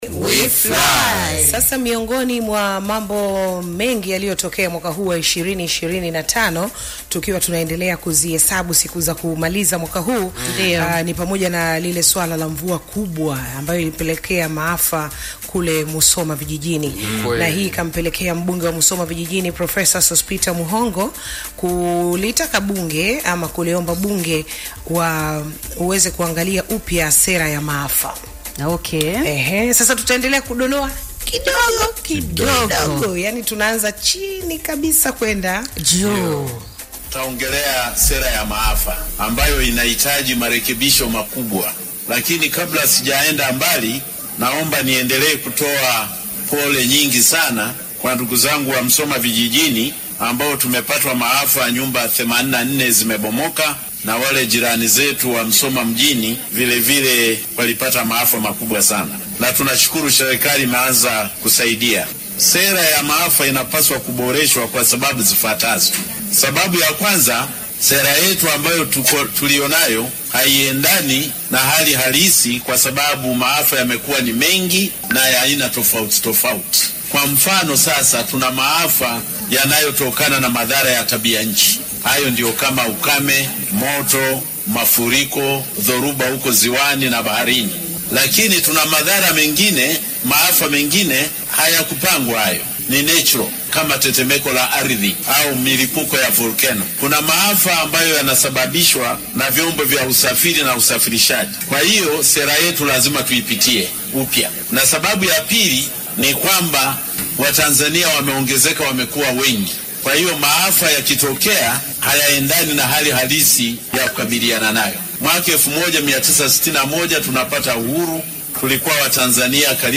Clip: Radio FM imeambatanishwa hapa
PROF-MUHONGO-DEC-16-EFM-REDIO-1.mp3